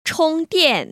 [chōng//diàn] 충띠앤  ▶